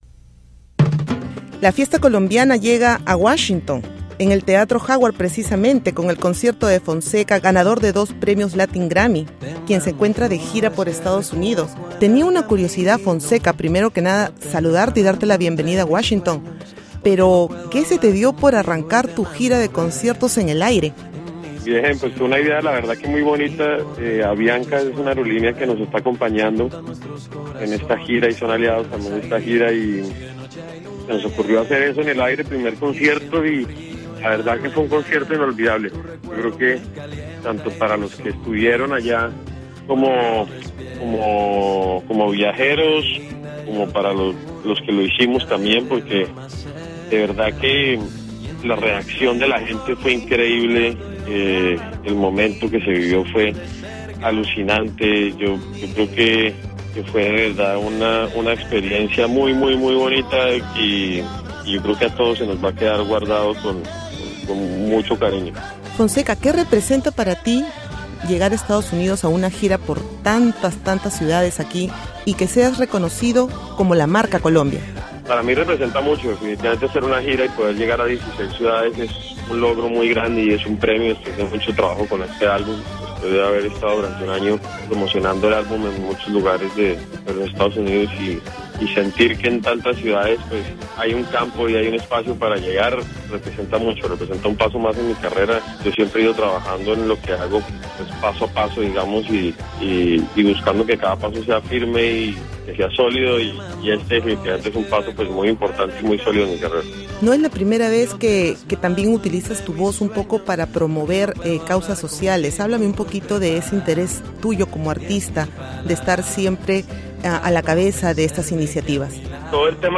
Entrevista al cantautor colombiano Fonseca